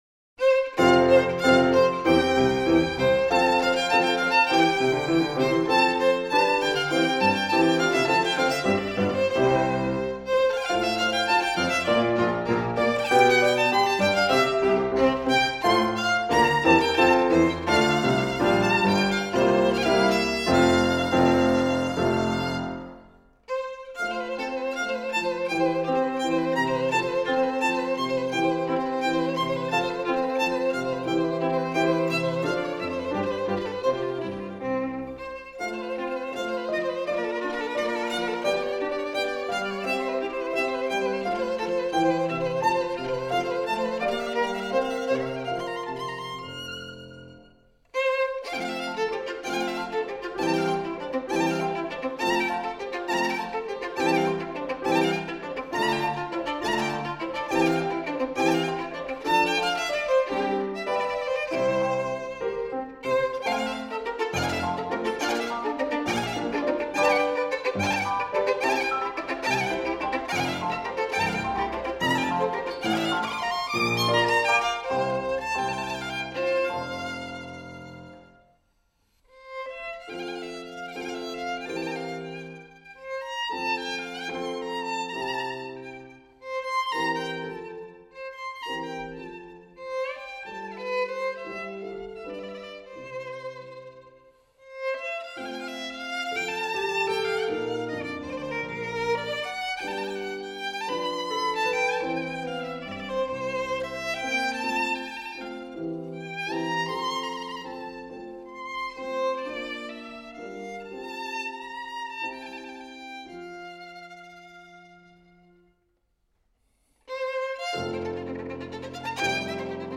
整张专辑如同行云流水，一气呵成。